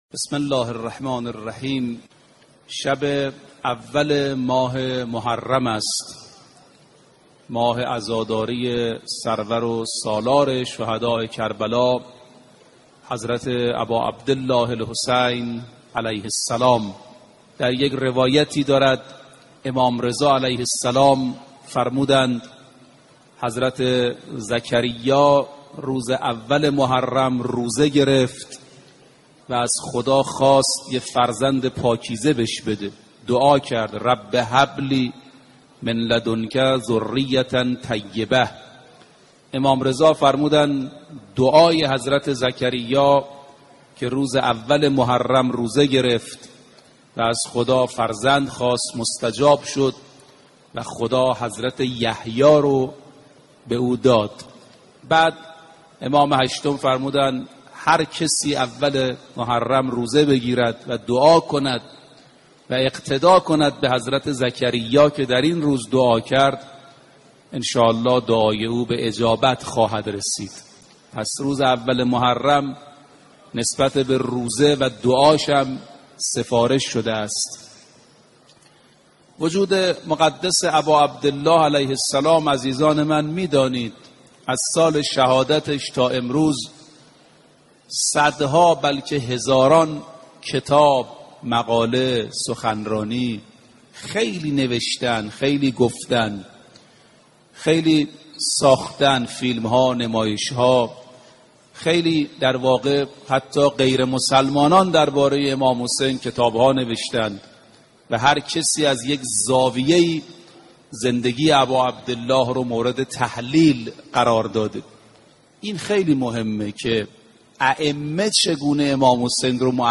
4 جلسه سخنرانی